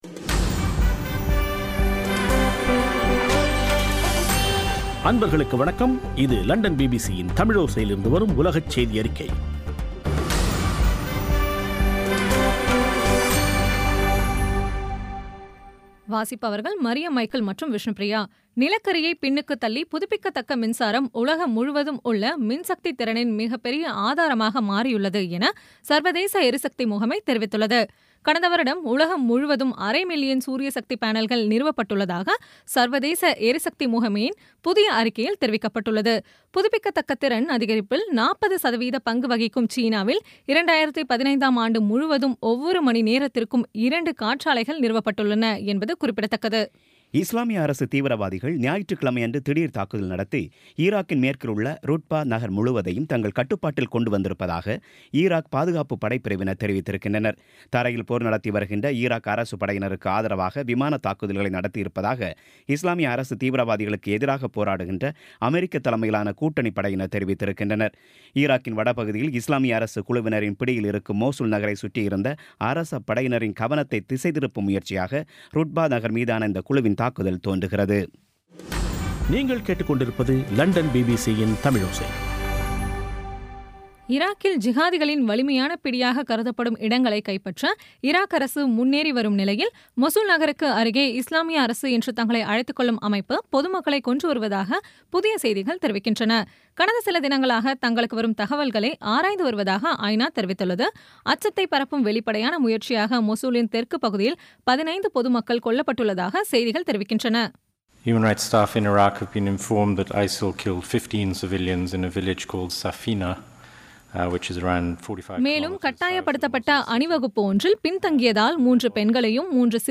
இன்றைய (அக்டோபர் 25ம் தேதி) பிபிசி தமிழோசை செய்தியறிக்கை